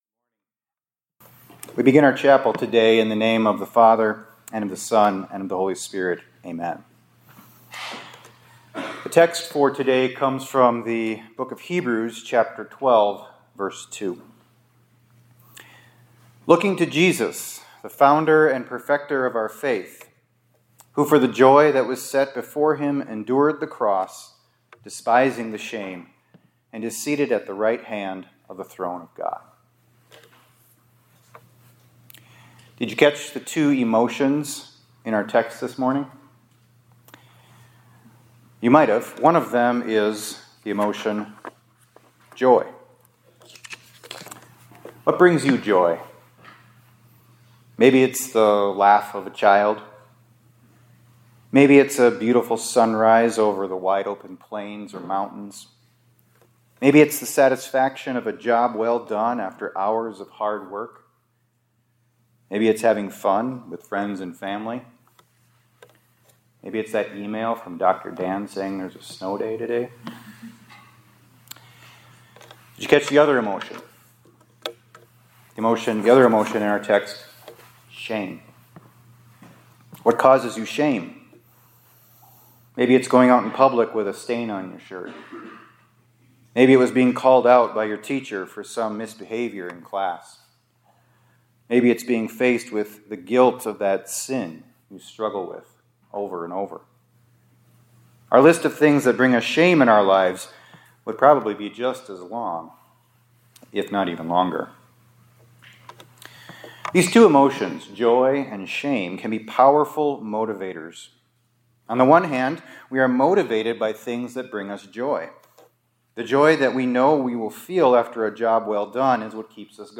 2026-03-25 ILC Chapel — The Joy and the Shame